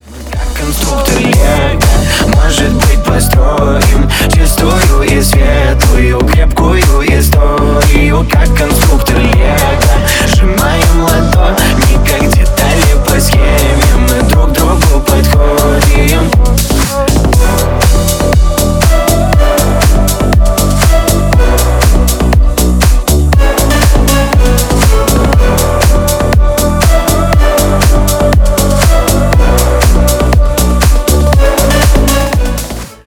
Ремикс # Поп Музыка
клубные # громкие